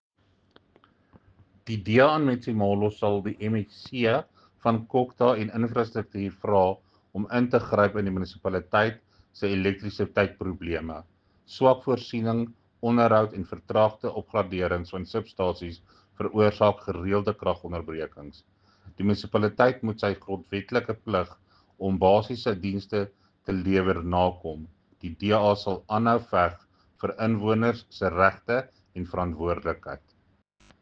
Afrikaans soundbites by Cllr Jacques Barnard.